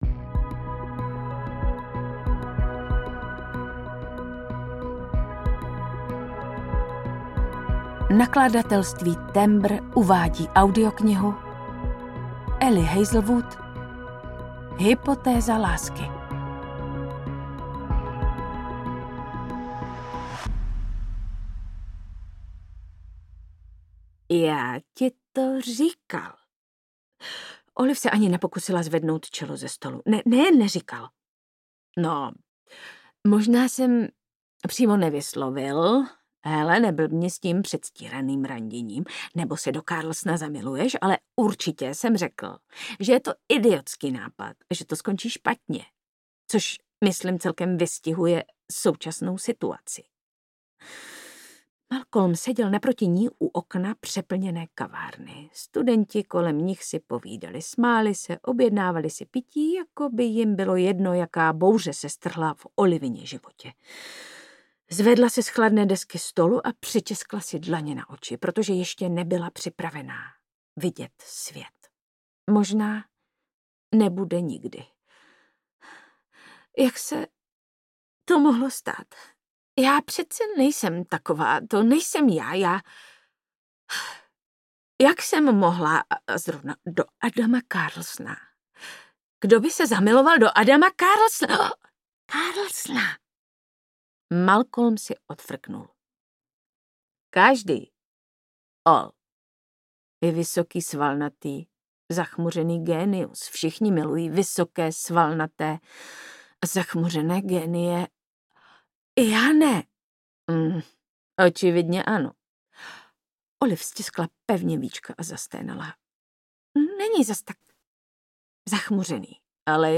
Hypotéza lásky audiokniha
Ukázka z knihy